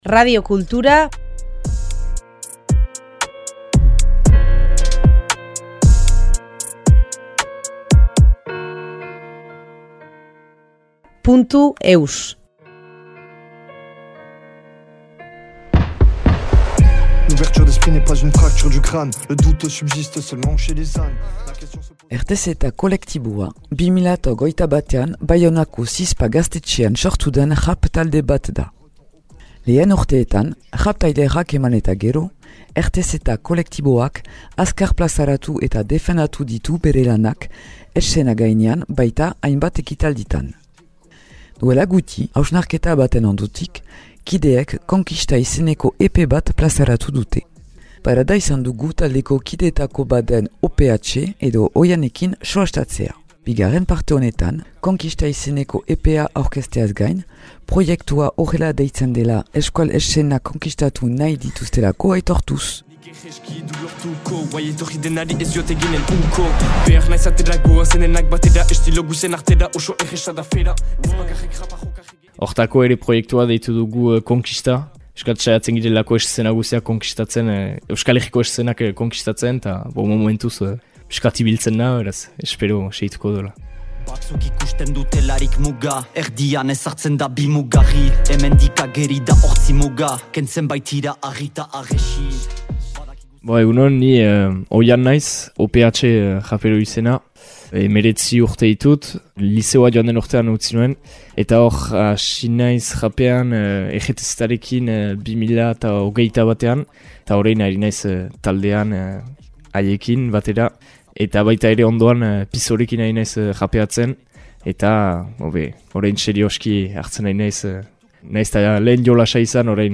Jatorria : Radiokultura